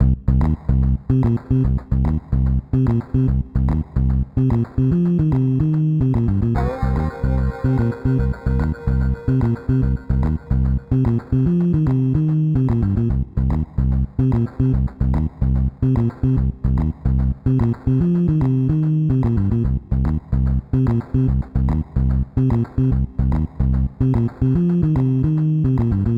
Melody in spirit noir burglars, or something close. Have a low-fi version.
Noire Crime Time (Low-Fi).ogg